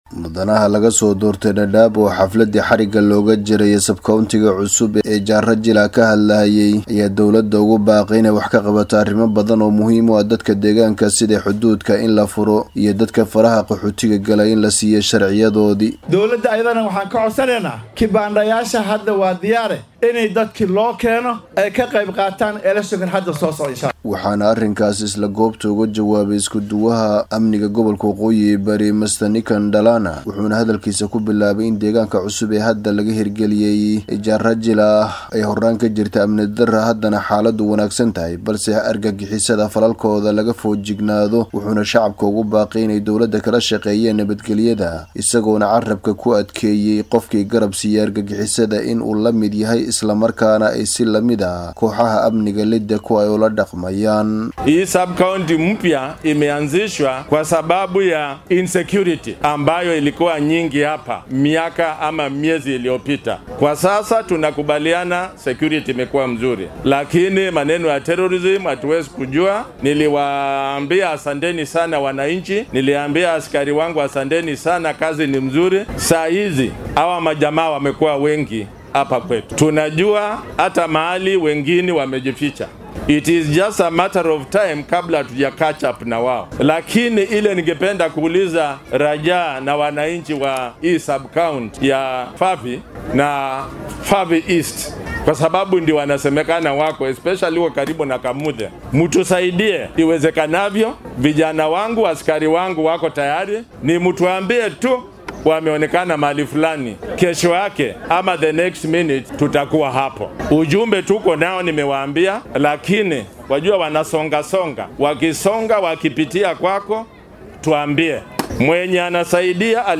Iskuduwaha ammaanka ee gobolka waqooyi bari Nicodemus Ndalana ayaa ka hadlay arrimo muhiim ah oo ay ka mid yihiin xuduudda xiran ee Kenya iyo Soomaaliya sidoo kale burcadnimo ka billaabatay qeybo ka mid ah deegaanka Dadaab.